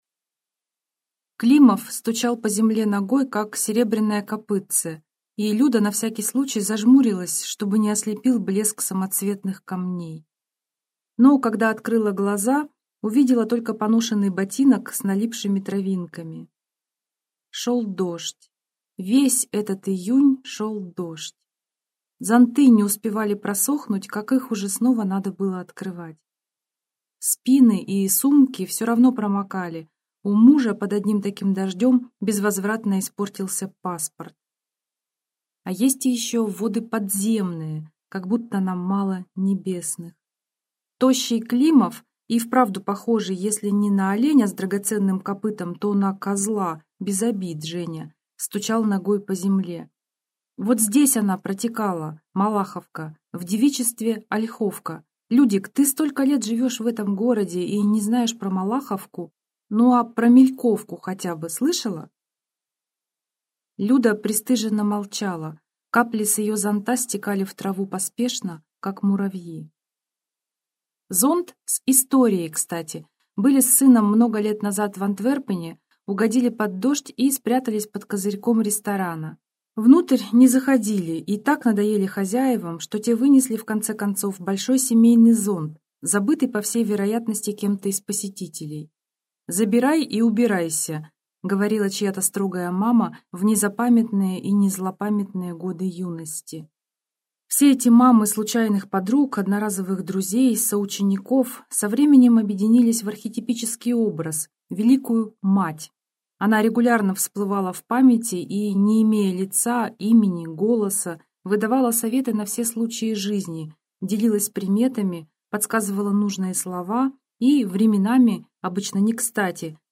Аудиокнига Спрятанные реки | Библиотека аудиокниг